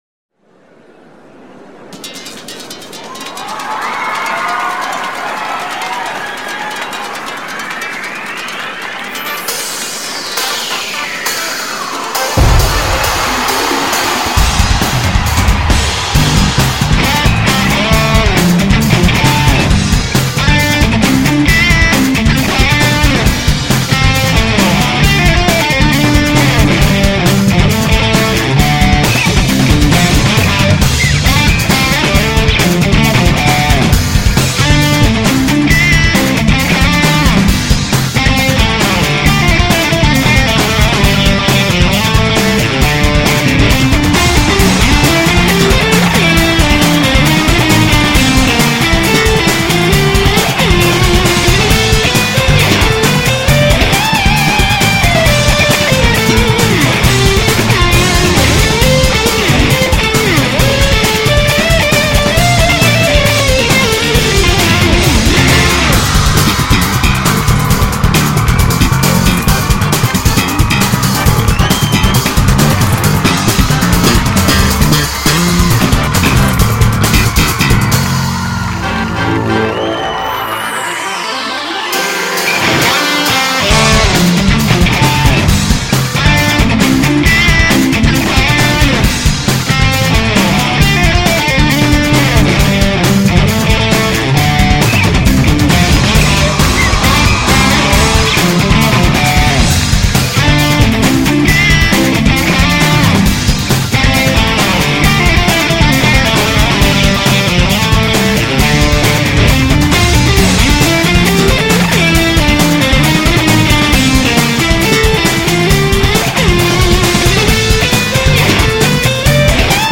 라틴느낌 나는 곡인데 그 곡의 라이브밴드 버전으로써